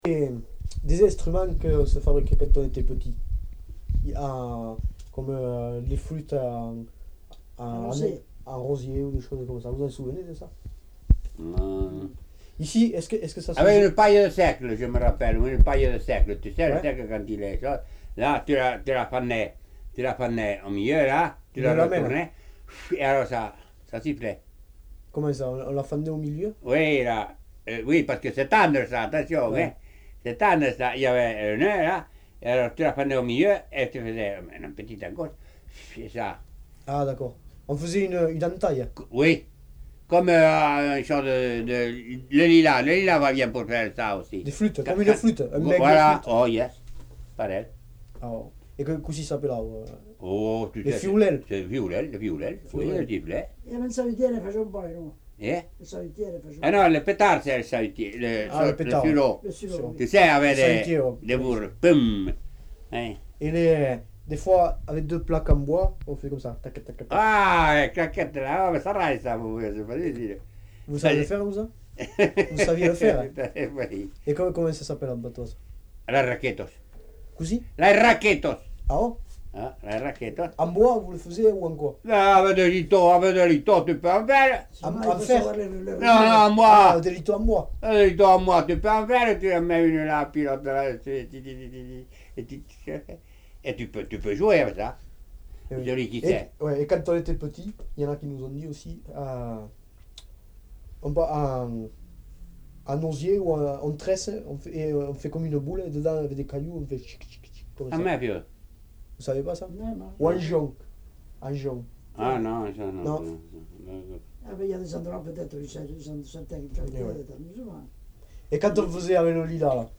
Genre : témoignage thématique
Instrument de musique : sifflet végétal
Contient un formulettte enfantine pour la fabrication des sifflets. L'inf. siffle dans une coquille de gland.